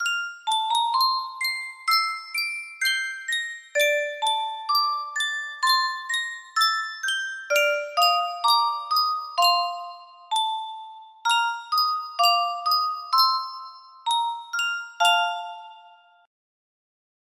Yunsheng Music Box - Pachelbel Canon in D 5382 music box melody
Full range 60